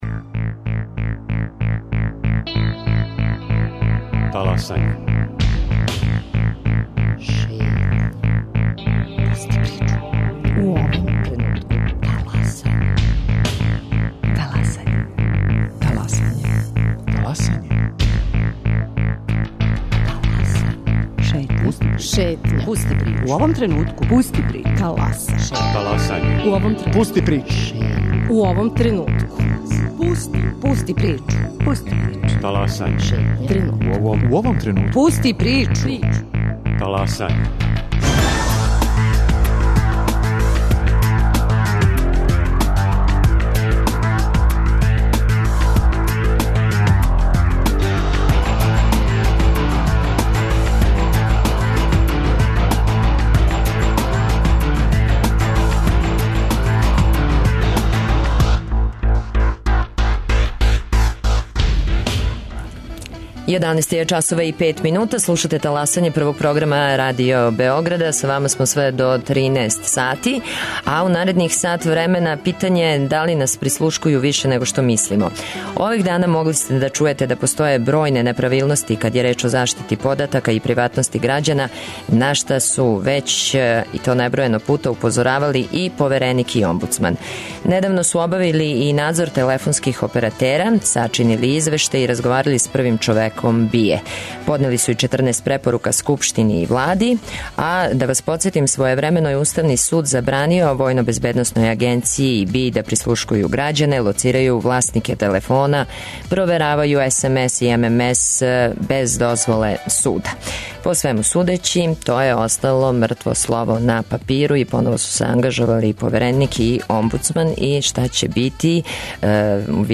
Гост Таласања: Родољуб Шабић, повереник за информације од јавног значаја и заштиту података о личности